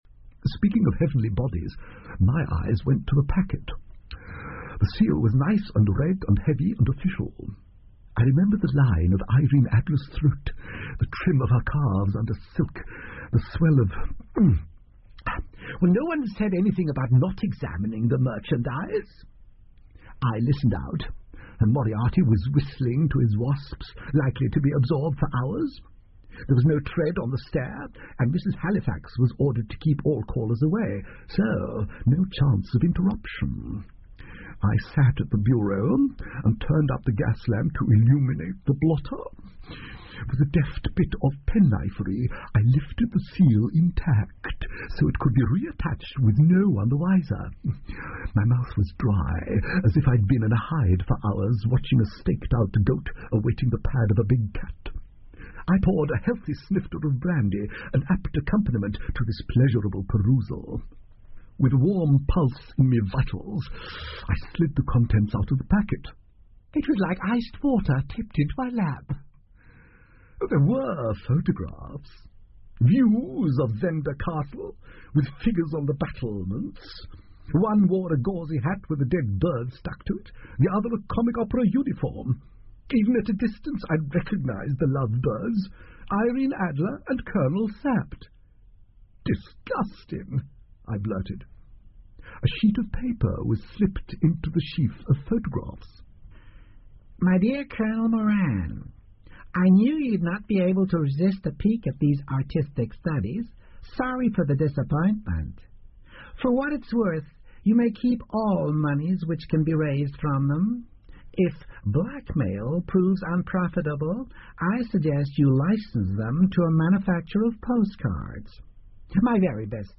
福尔摩斯广播剧 Cult-A Shambles In Belgravia 6 听力文件下载—在线英语听力室